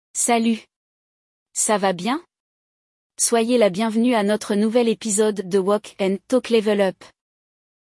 No episódio de hoje, vamos escutar um diálogo entre dois amigos que tentam descobrir quem vandalizou as lixeiras do bairro.